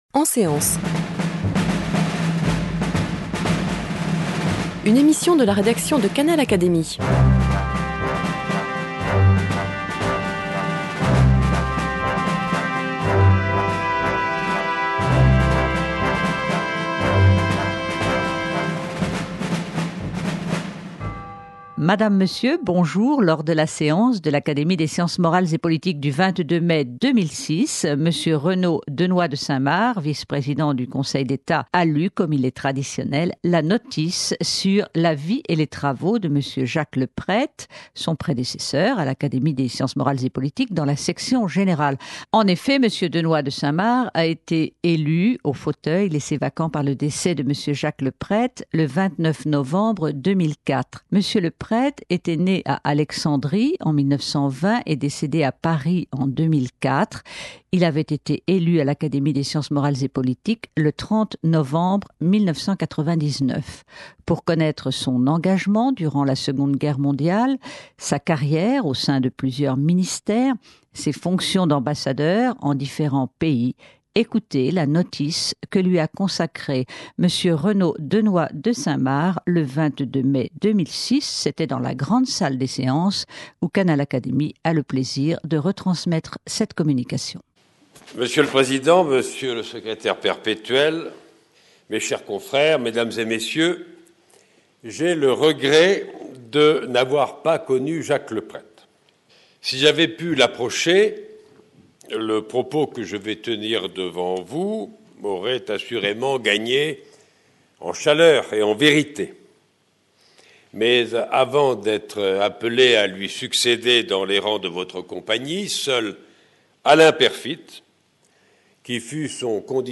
M. Renaud Denoix de Saint-Marc a donné lecture, le lundi 22 mai, devant ses confrères de l’Académie des sciences morales et politiques, de la notice qu’il a consacrée à son prédécesseur dans la section générale, M.Jacques Leprette.